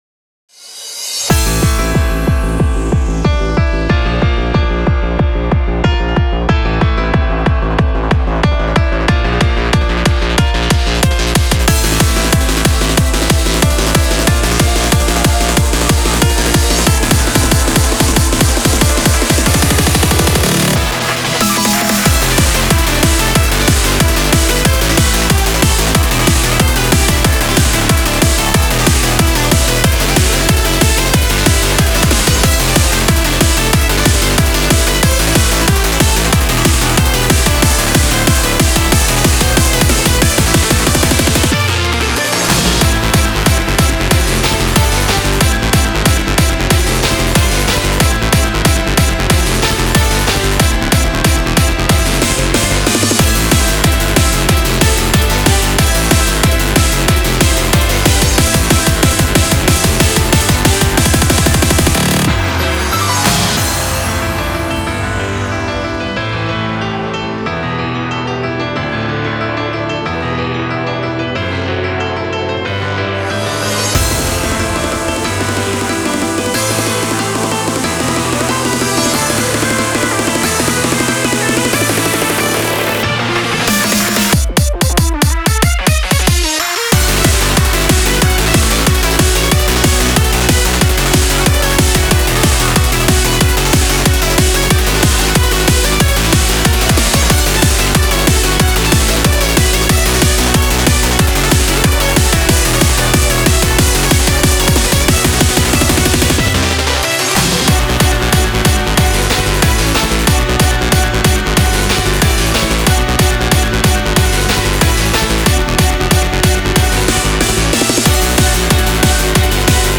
BPM185
Audio QualityMusic Cut